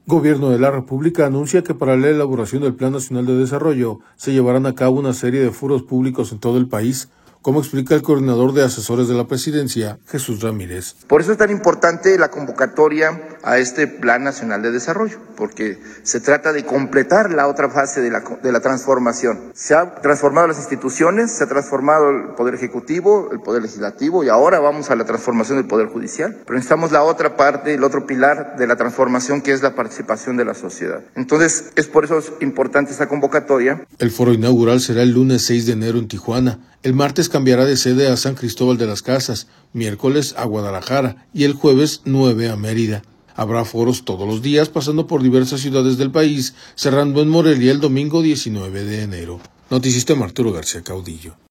Gobierno de la República anuncia que para la elaboración del Plan Nacional de Desarrollo se llevarán a cabo una serie de foros públicos en todo el país, como explica el coordinador de asesores de la Presidencia, Jesús Ramírez.